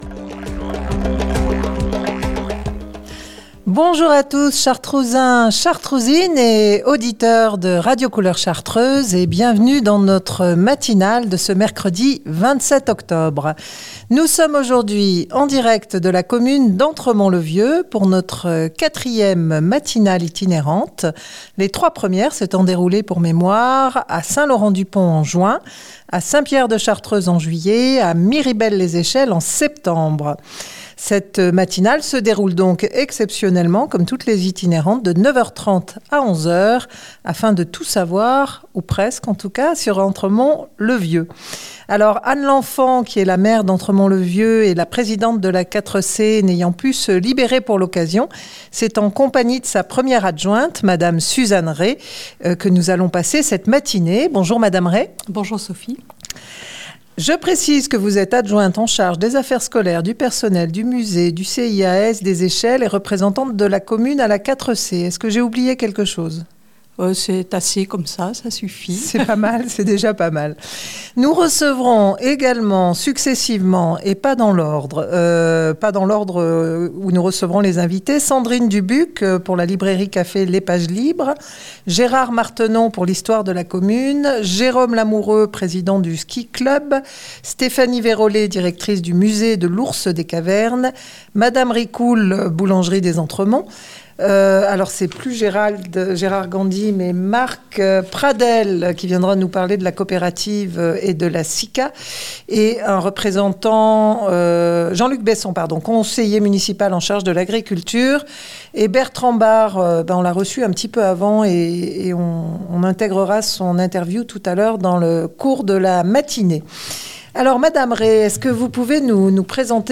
C’est à Entremont-le-Vieux que s’est déroulé notre 4ème matinale itinérante de l’année